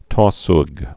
(tôsg)